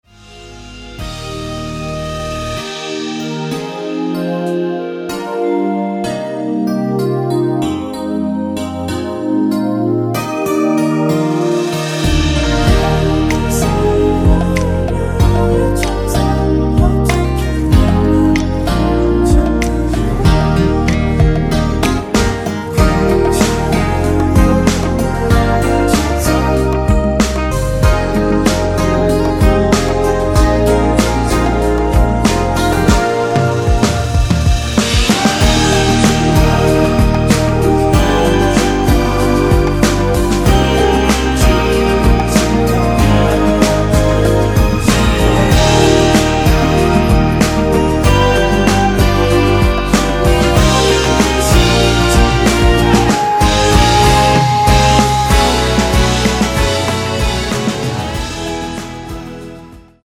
원키에서(-1)내린 멜로디와 코러스 포함된 MR입니다.(미리듣기 참고)
Eb
앞부분30초, 뒷부분30초씩 편집해서 올려 드리고 있습니다.